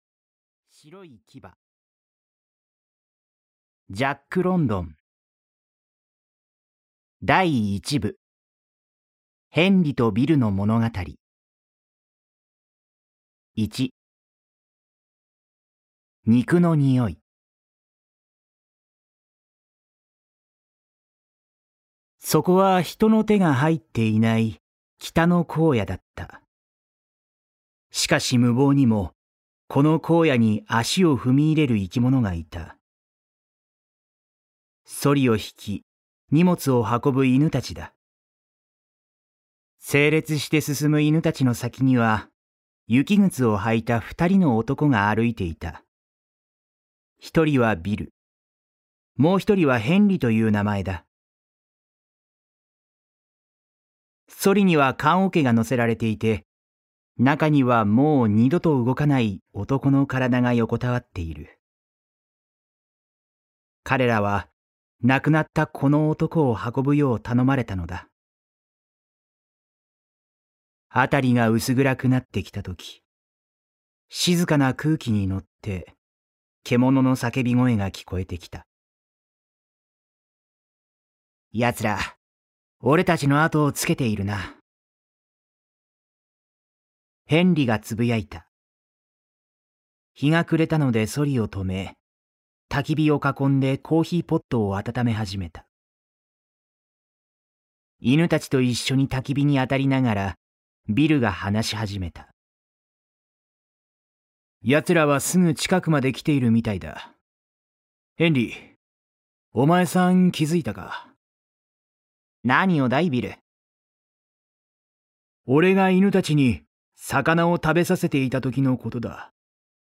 [オーディオブック] 白い牙（こどものための聴く名作 15）